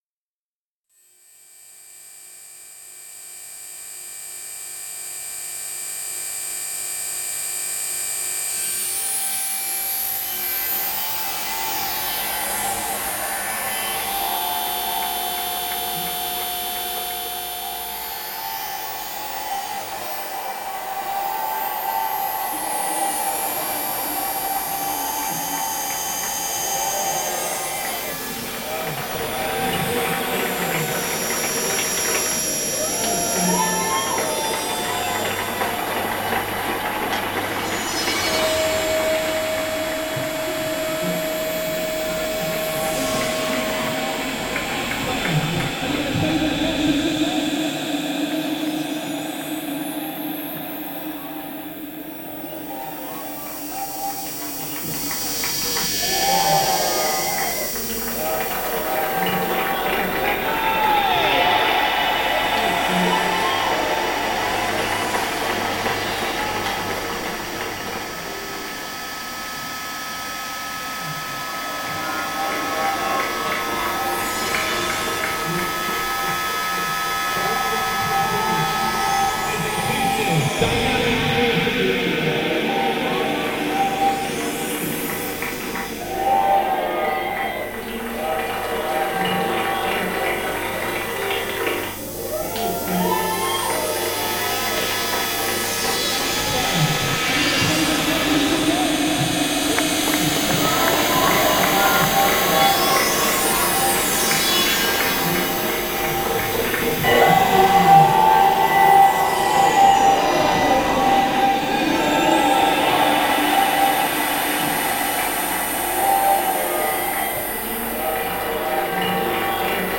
Pro-EU march in London reimagined